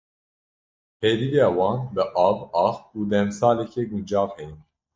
/ɑːx/